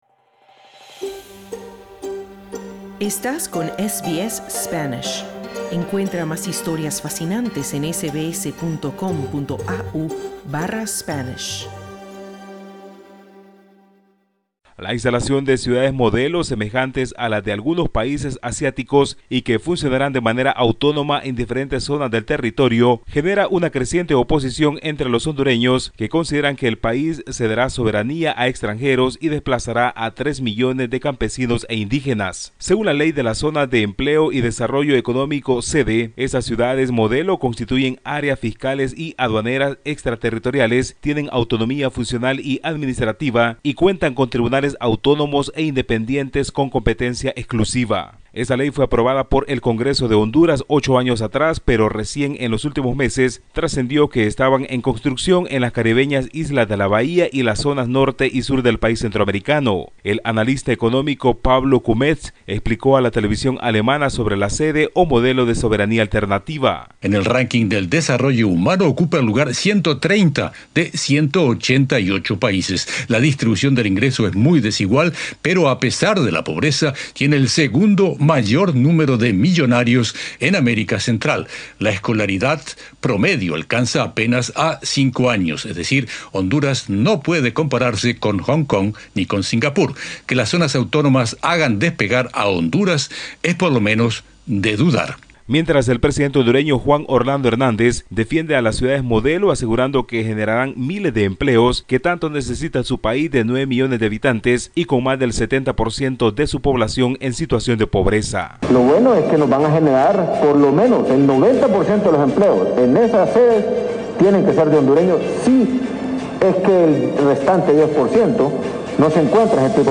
La instalación de "ciudades modelo", semejantes a las de algunos países asiáticos, en diferentes zonas de Honduras, está generando una creciente oposición entre la población que teme perder la soberanía a extranjeros y el desplazamiento de 3 millones de campesinos e indígenas. Escucha el informe…